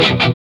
GTR CHUCK00L.wav